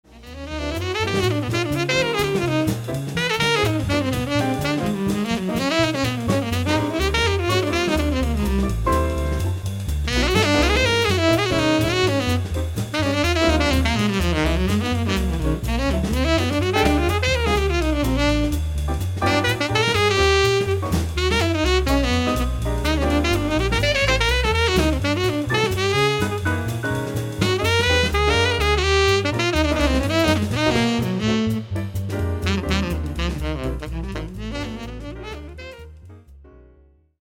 great playing again.. still too soft for me.
Best sampled sax solo anywhere hands down.